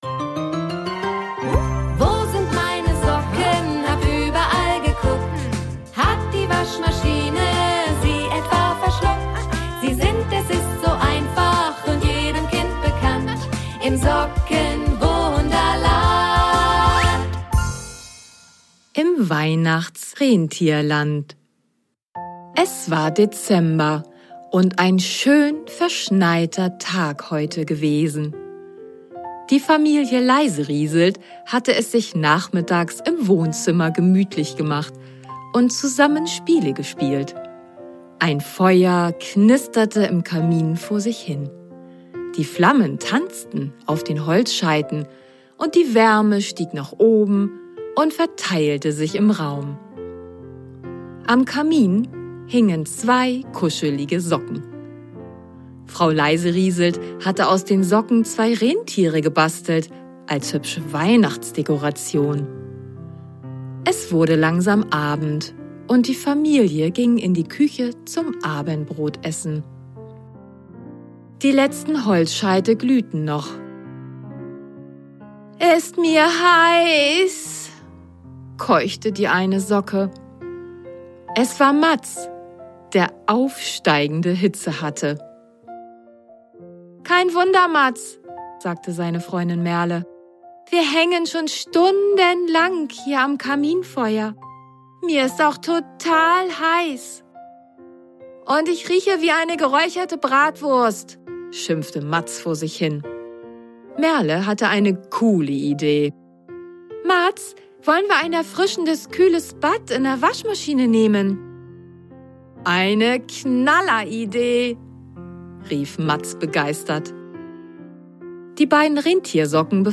Die beiden Socken lernen das Rentier Mumpf kennen und erleben wahrhaft Erstaunliches. Fitness muss sein vor der großen Weihnachtsreise mit dem Weihnachtsmann! Viel Freude beim Lauschen und möge ich Euch eine besinnliche Stimmung schenken :-) Und wer mag, am Ende habe ich noch ein paar Fragen zur Geschichte.... vielleicht könnt Ihr diese ja beantworten?